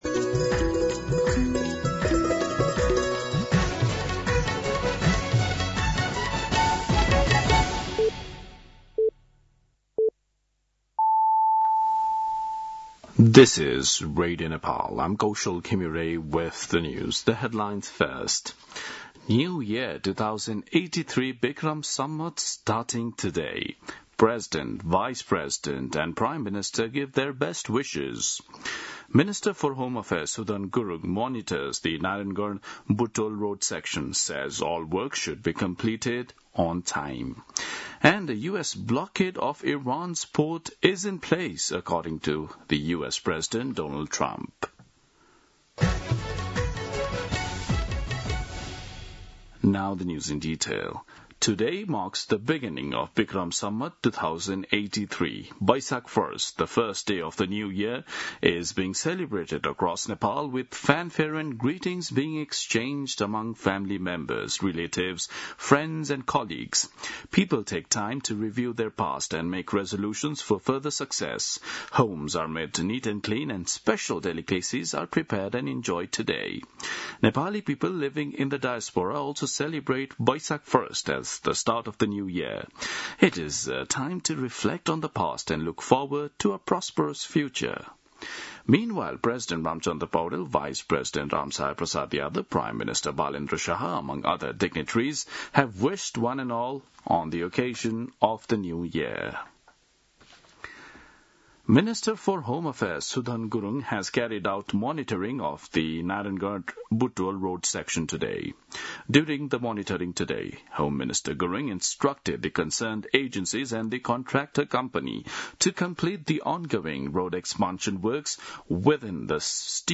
दिउँसो २ बजेको अङ्ग्रेजी समाचार : १ वैशाख , २०८३
2-pm-News-01.mp3